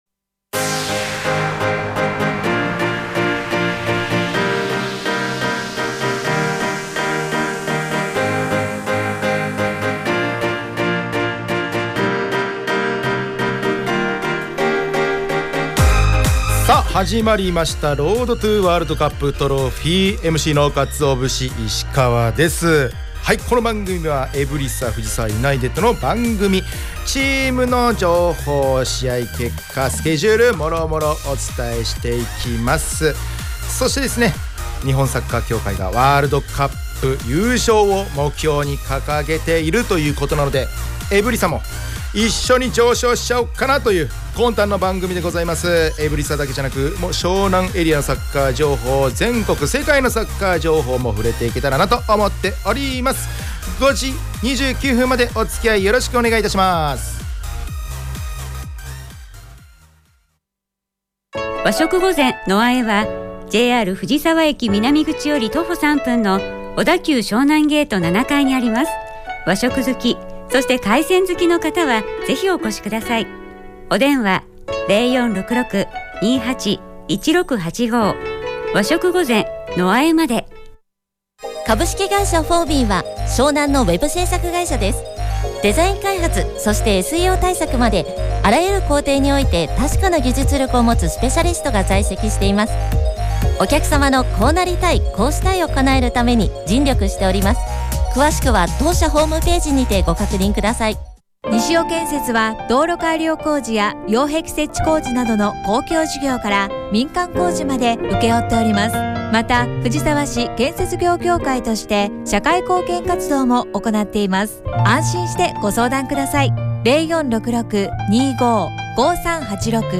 エブリサ藤沢ユナイテッドが提供する藤沢サッカー専門ラジオ番組『Road to WC Trophy』の第2期の第33回放送が11月15日(金)17時に行われました☆